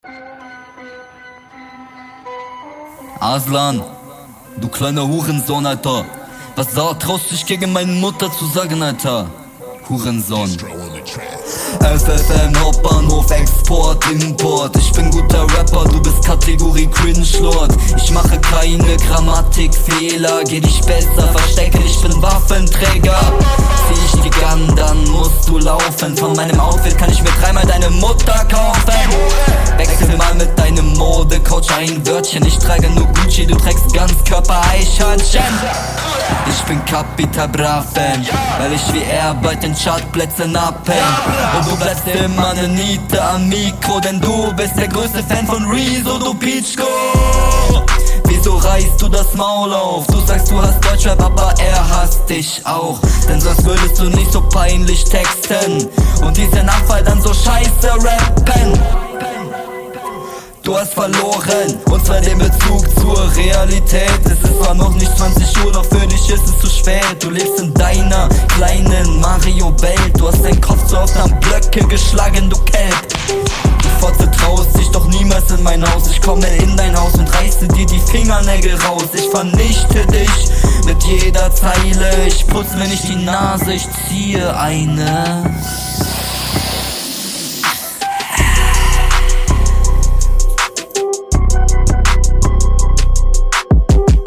Der Einstieg ist gut geflowt, danach kackste rein durch deine Betonungen.